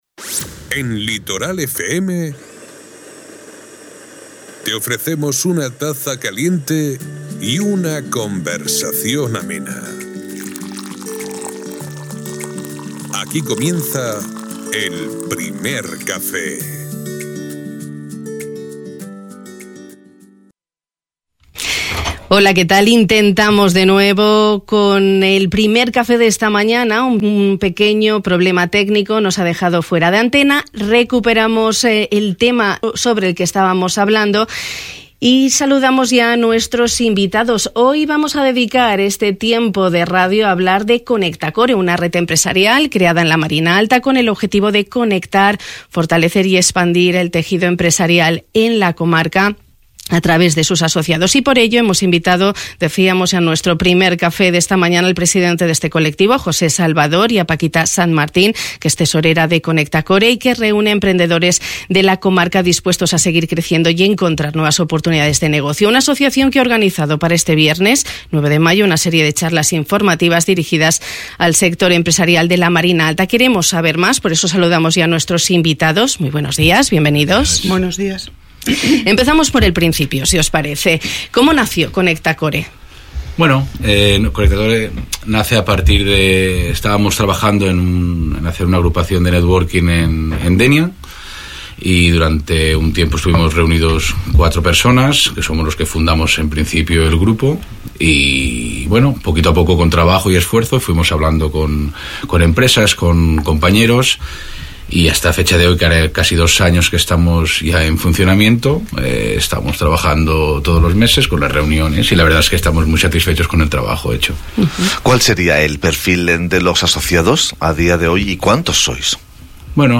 Un tiempo de radio que nos ha servido para conocer ConectaCore, una red empresarial creada en la Marina Alta con el objetivo de conectar, fortalecer y expandir el tejido empresarial de la comarca a través de sus asociados.